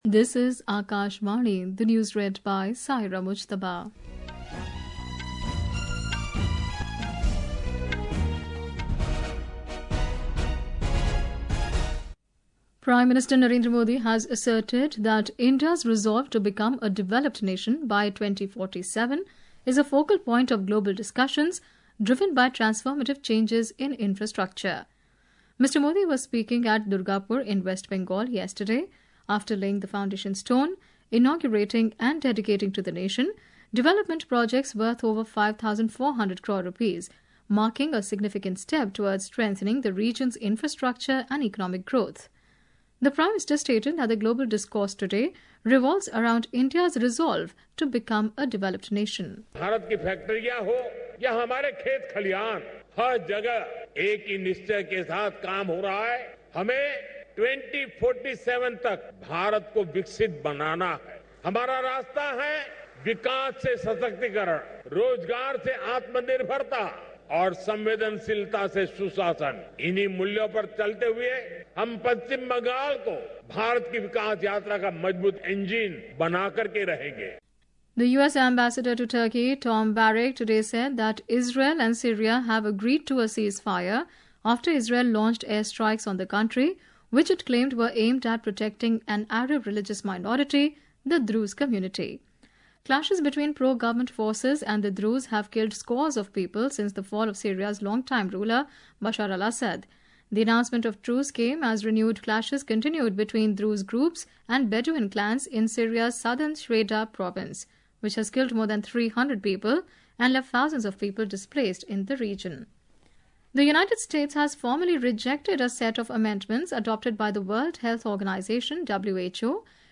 Hourly News Hourly News